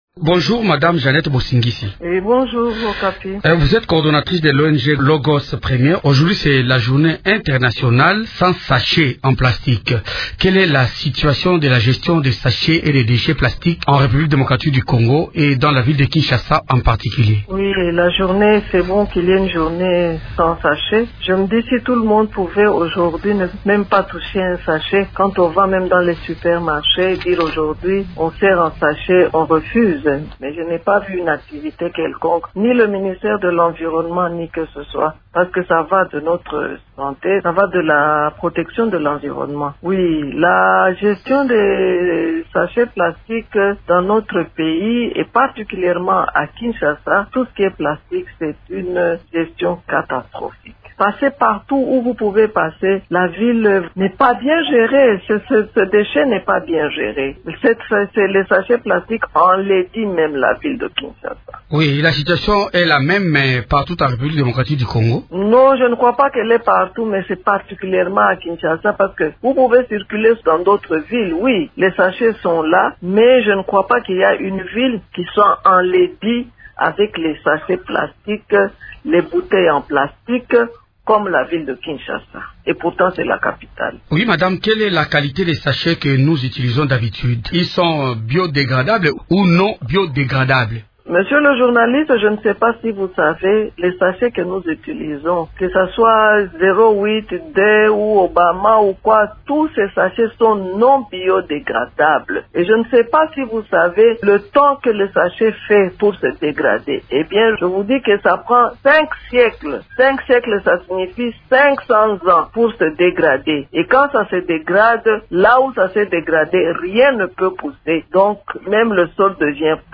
«Ce déchet n’est pas bien géré», déclare-t-elle dans un entretien accordé à Radio Okapi à l’occasion de la journée mondiale sans sacs plastiques.